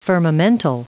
Prononciation du mot firmamental en anglais (fichier audio)
Prononciation du mot : firmamental